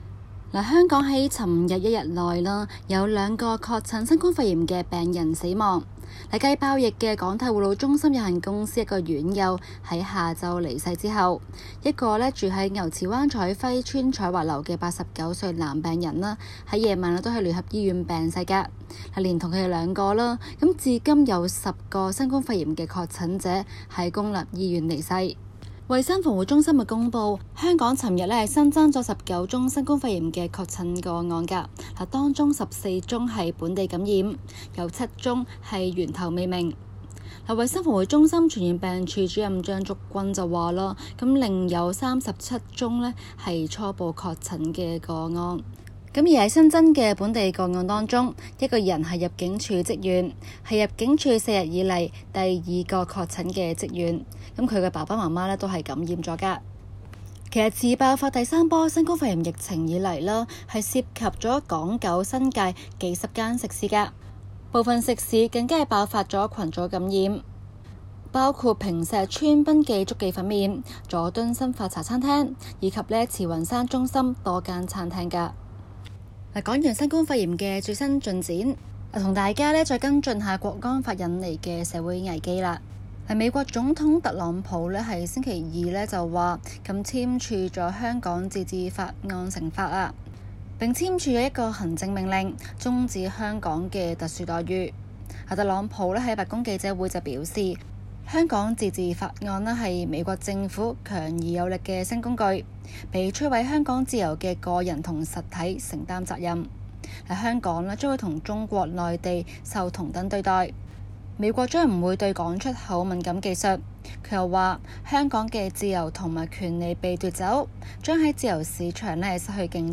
今期【中港快訊 】環節報道香港爆發第三波疫情以來， 記錄得一天內有兩名病人病逝消息。